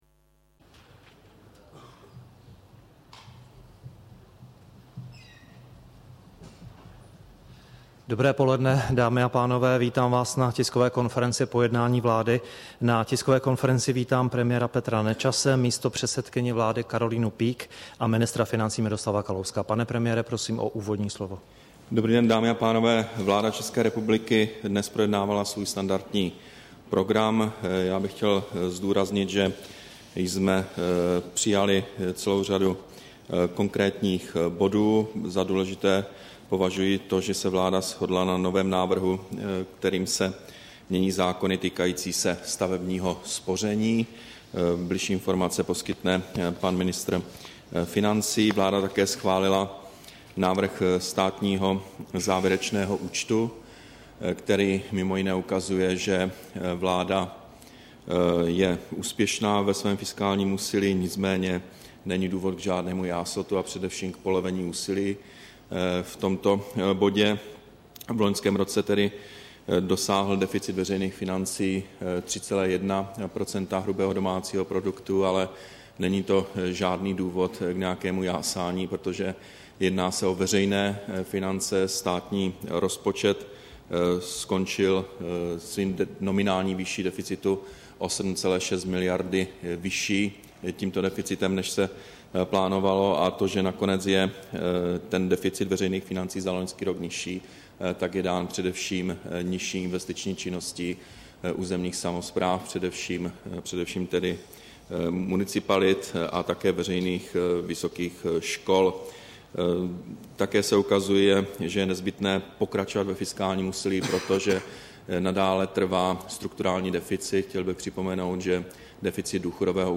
Tisková konference po jednání vlády, 18. dubna 2012